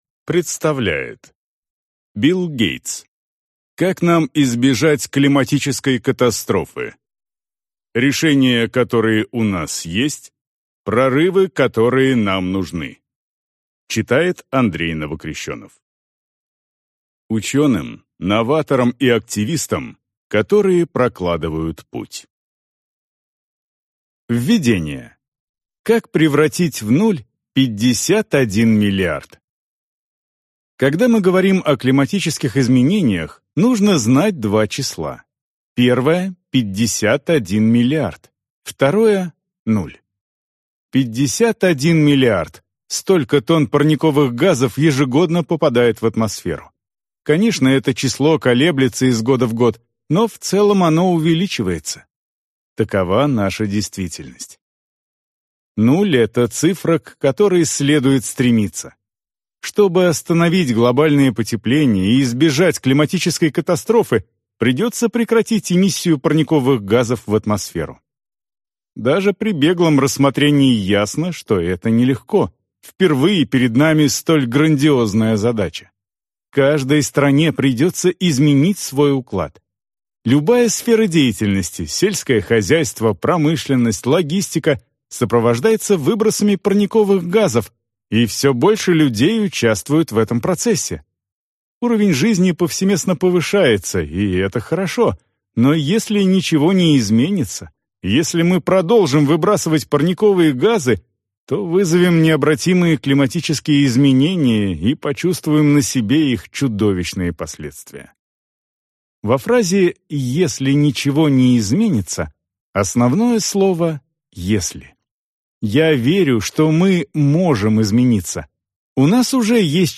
Аудиокнига Как нам избежать климатической катастрофы | Библиотека аудиокниг